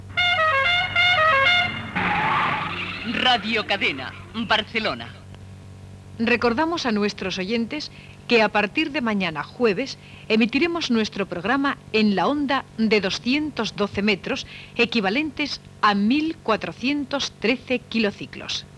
Indicatiu i avís del canvi de freqüència.